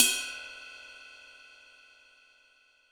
• Large Room Drum Crash Sample D# Key 23.wav
Royality free drum crash tuned to the D# note. Loudest frequency: 6659Hz
large-room-drum-crash-sample-d-sharp-key-23-1Az.wav